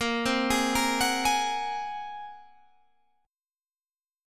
A#7sus2#5 Chord